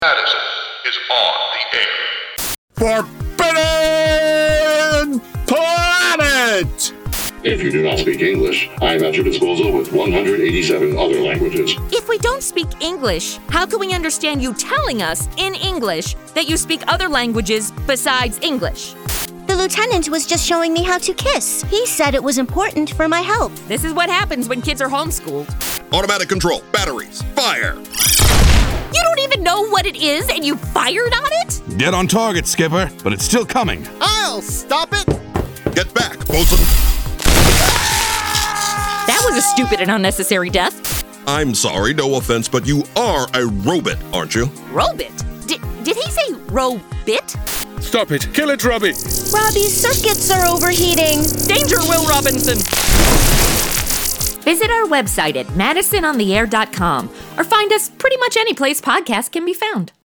The place is a paradise, except for a dark force that threatens their lives!  Adapted from the original radio play by the 1950’s Australian series, The Caltex Theatre.